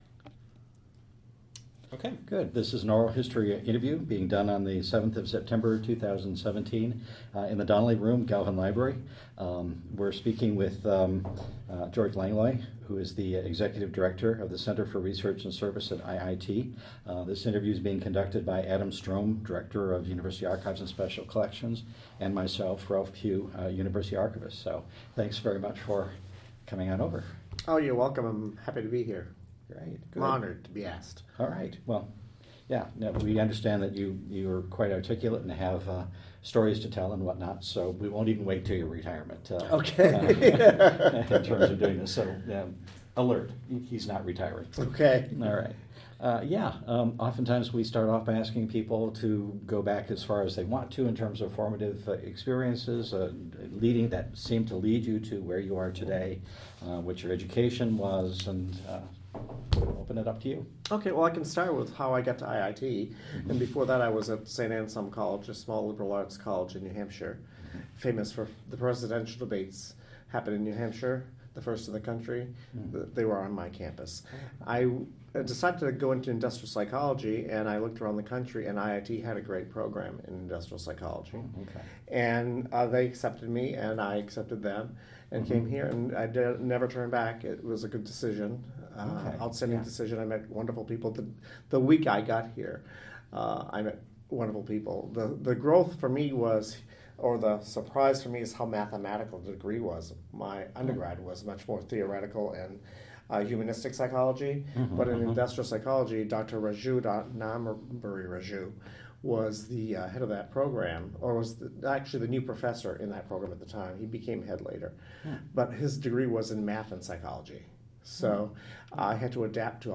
Type Interview